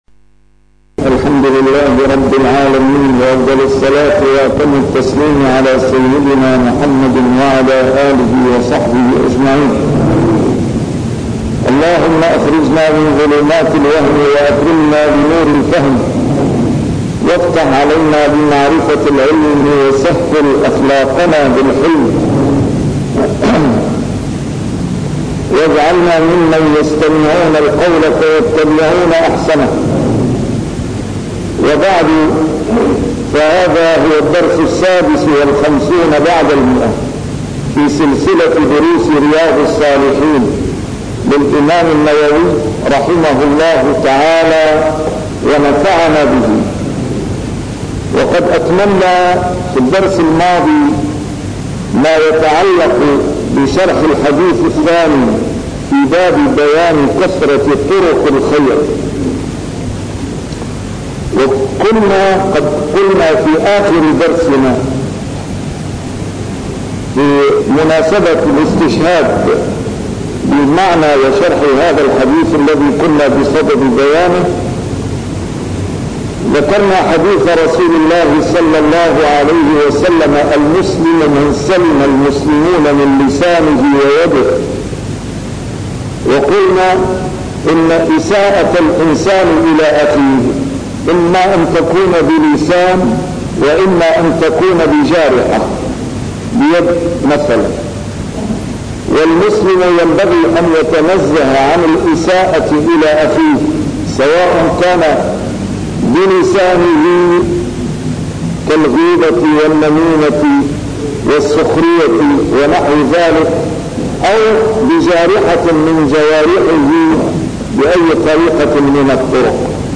A MARTYR SCHOLAR: IMAM MUHAMMAD SAEED RAMADAN AL-BOUTI - الدروس العلمية - شرح كتاب رياض الصالحين - 156- شرح رياض الصالحين: كثرة طرق الخير